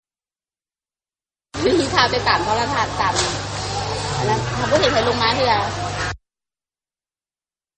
ຂອງພະນັກງານຍິງປະຈໍາສະໜາມບິນແຫ່ງນັ້ນຄົນນຶ່ງ: